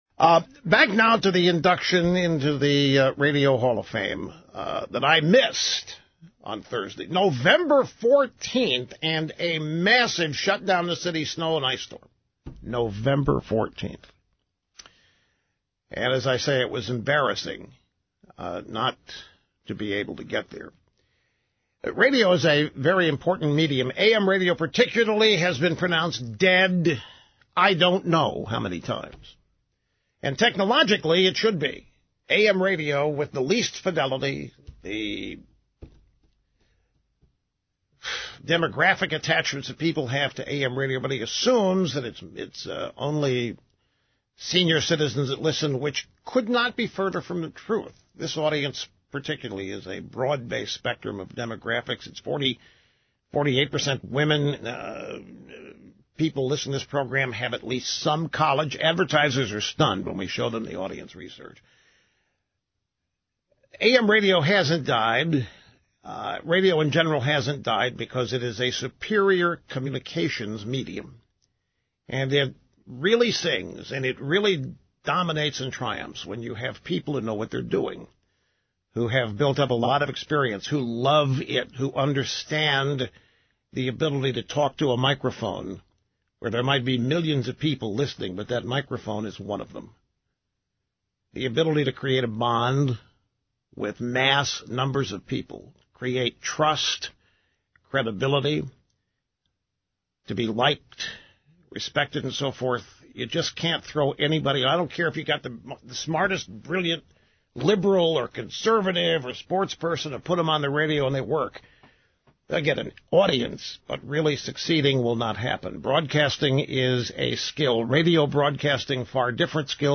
Rush couldn’t make it to the Radio Hall of Fame last week because of the snow but inducted Mark on the air today (November 19, 2018)